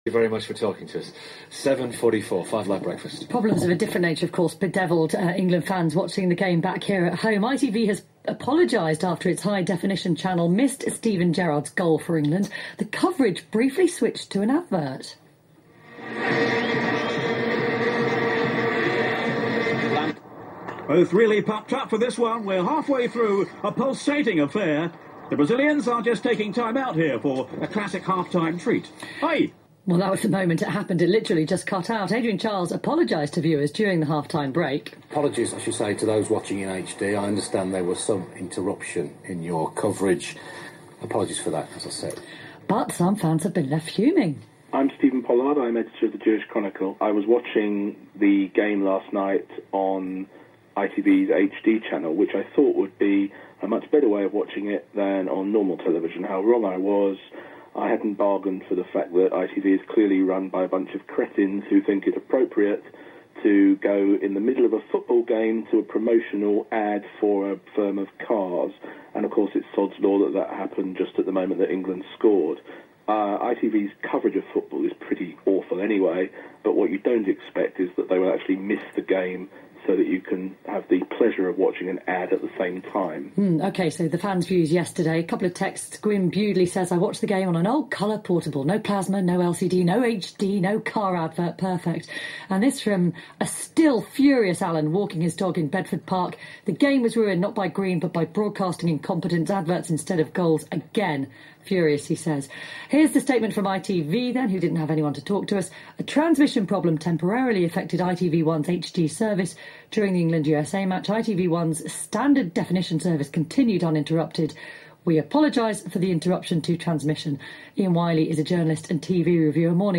I was subsequently asked to talk about the incident on BBC Radio Five Live’s World Cup Breakfast Show this morning.
As you will have deduced from that interview, I actually feel very sorry for the team at ITV.